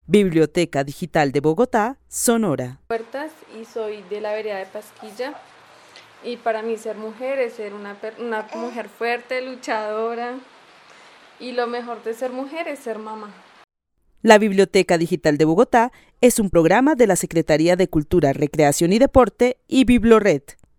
Narración oral de una mujer que vive en la vereda de Pasquilla, zona rural de Bogotá, quien considera que ser mujer es ser fuerte y luchadora. Destaca que lo mejor de ser mujer es ser mamá. El testimonio fue recolectado en el marco del laboratorio de co-creación "Postales sonoras: mujeres escuchando mujeres" de la línea Cultura Digital e Innovación de la Red Distrital de Bibliotecas Públicas de Bogotá - BibloRed.